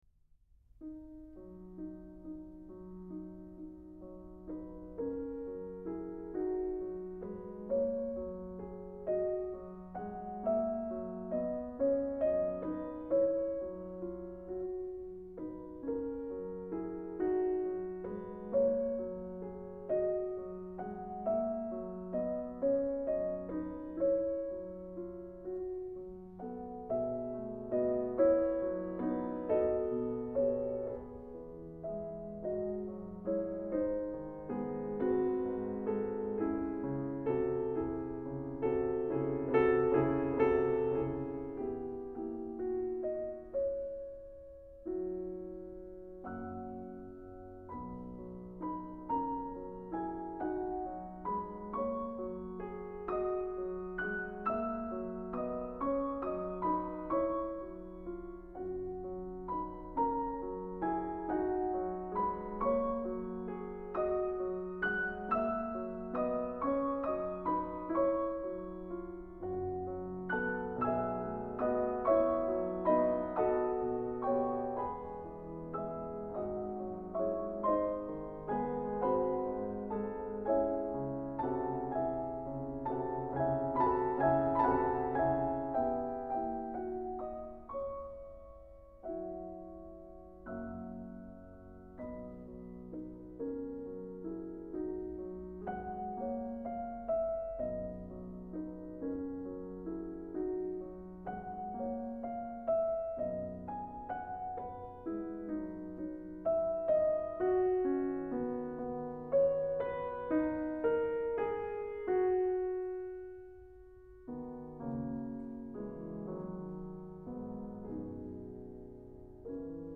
Juegos de Niños Op.22 (versión original para piano a cuatro manos)
Música clásica